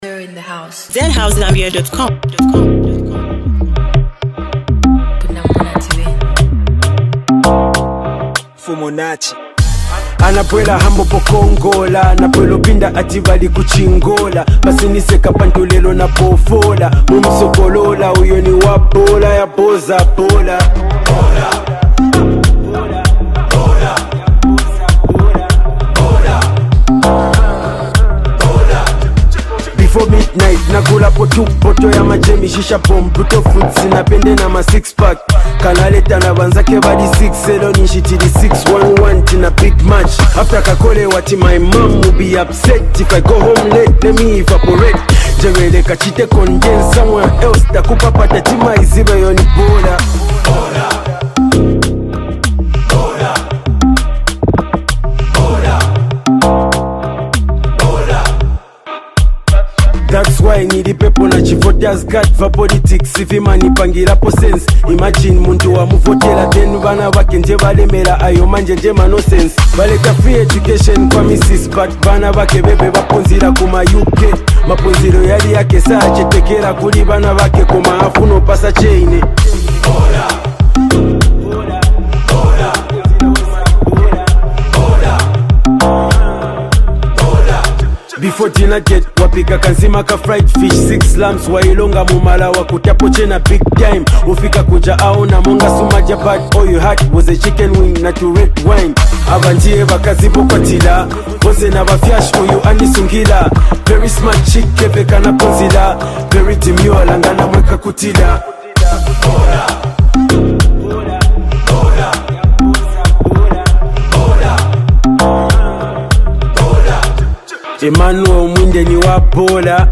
unleashes pure energy